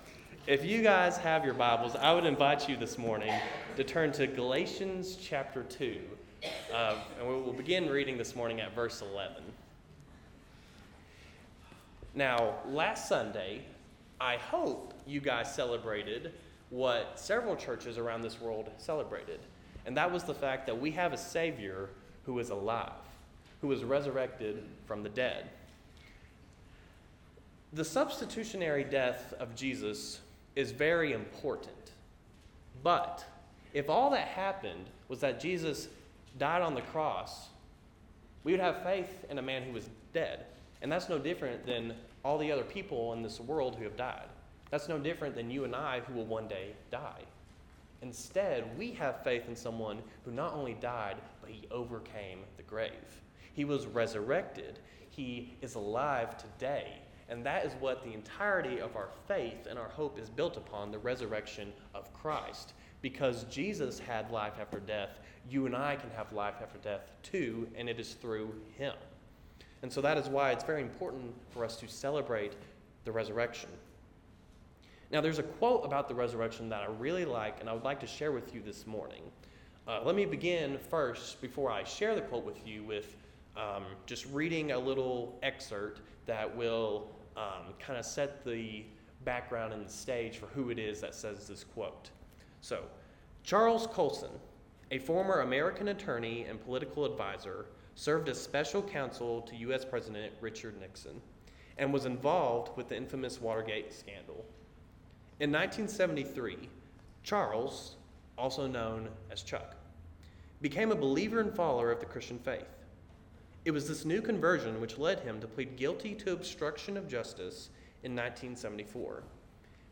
YouVersion Interactive Notes Series: Non-Series Messages